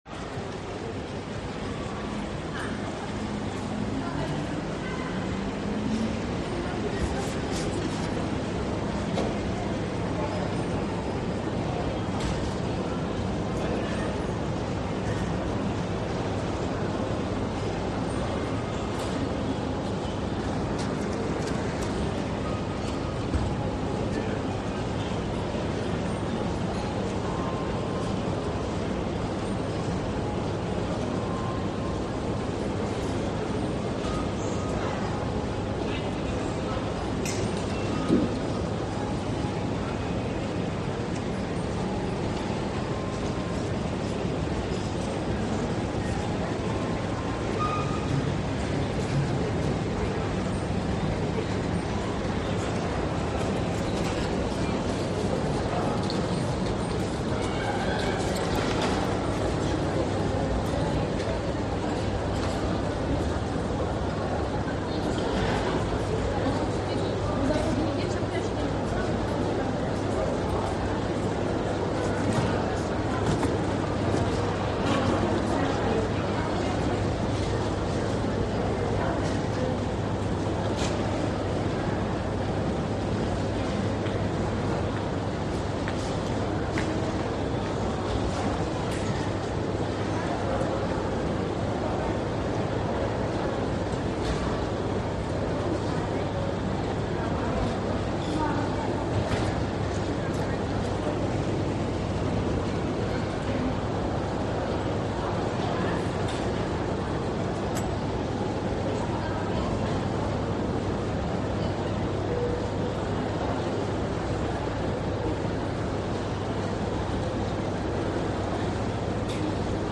Nagranie nr 11, Pasaż Grunwaldzki, Wrocław, Wejście Główne, 17 grudnia 2024, godz. 16:19, czas trwania: 2 min. 34 s.
Pasaz-Grunwaldzki-Wejscie-Gowne.mp3